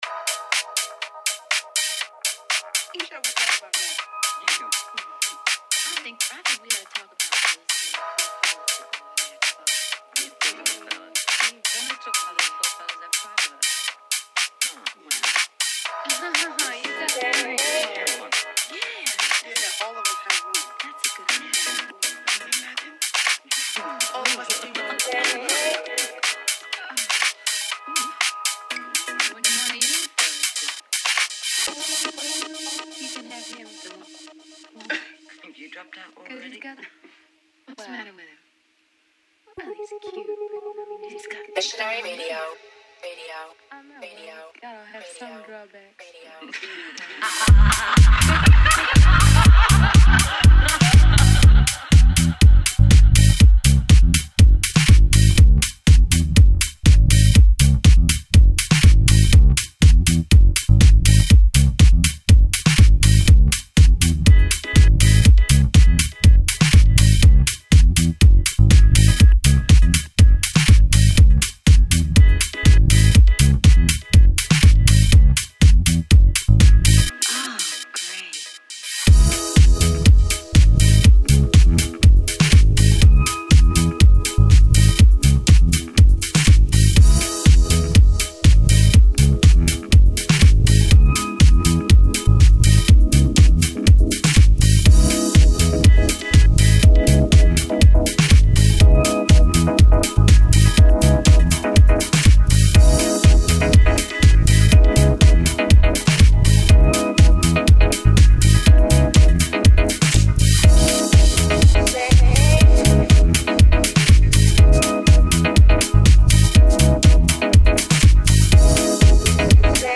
Online House, Tribal, Tech House, Trance and D&B.